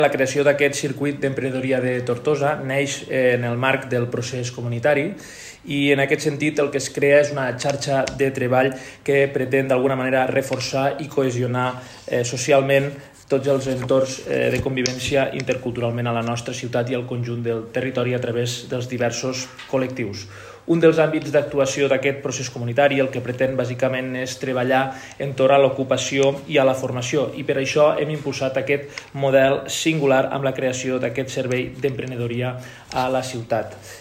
Un treball en xarxa imprescindible tal com ha destacat el regidor d’Acció Cívica  i Comunitaria, Victor Grau.